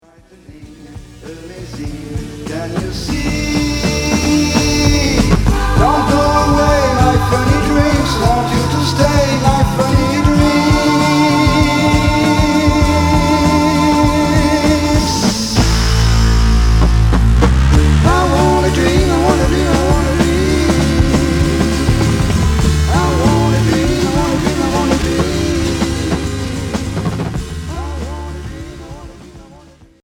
Pop à fuzz